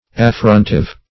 Search Result for " affrontive" : The Collaborative International Dictionary of English v.0.48: Affrontive \Af*front"ive\, a. Tending to affront or offend; offensive; abusive.
affrontive.mp3